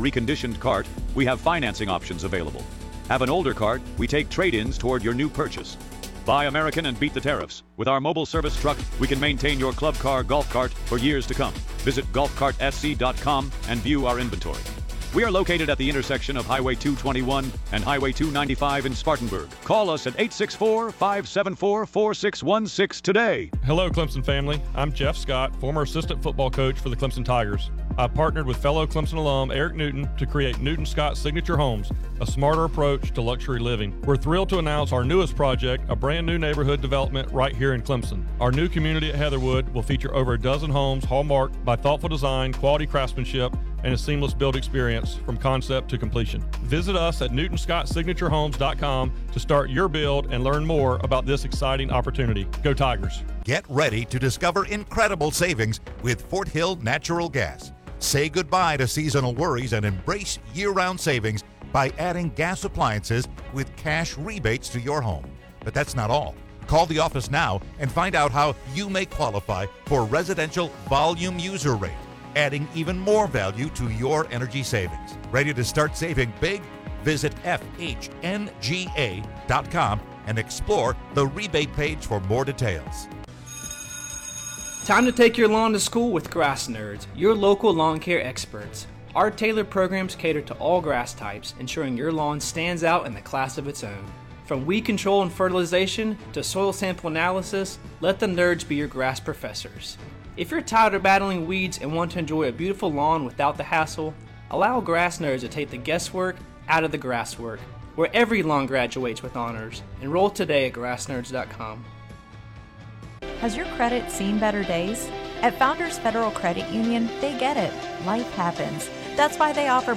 He brings his fiery and passionate personality to the airwaves every day, entertaining listeners with witty comments, in depth analysis and hard-hitting interviews.